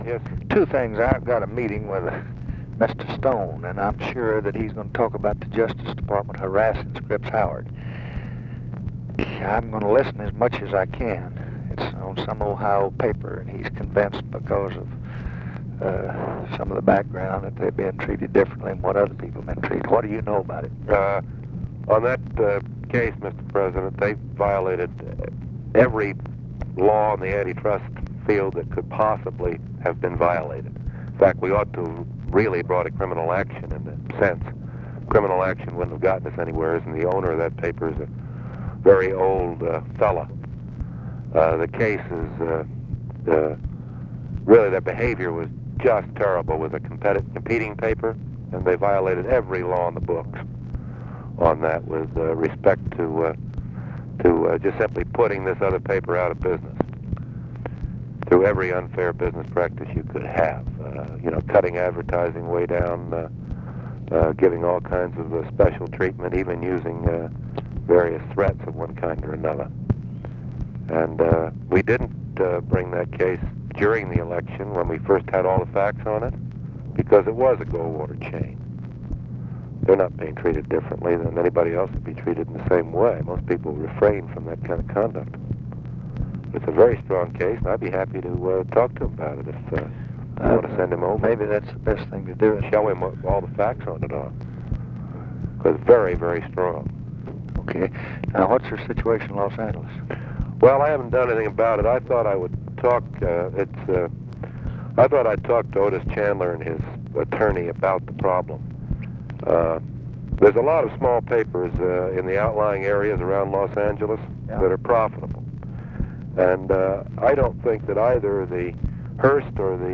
Conversation with NICHOLAS KATZENBACH, December 14, 1964
Secret White House Tapes